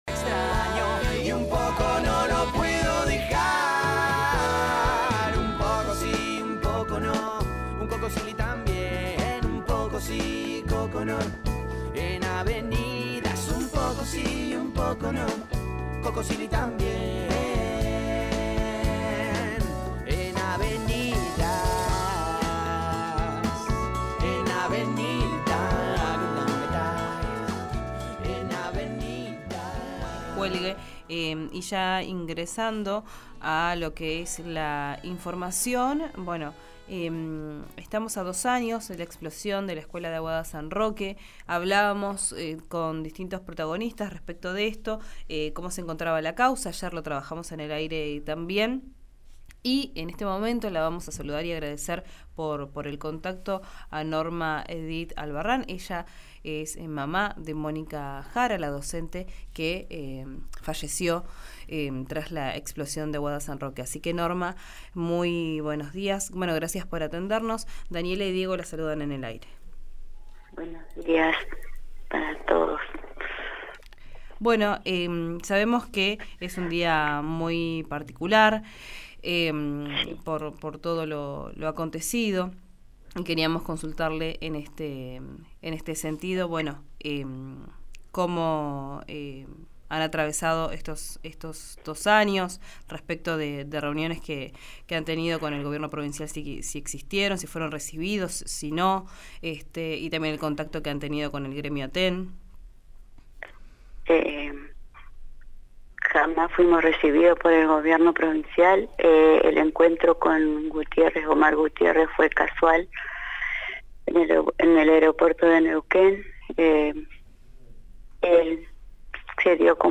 habló con RÍO NEGRO RADIO sobre esta jornada particular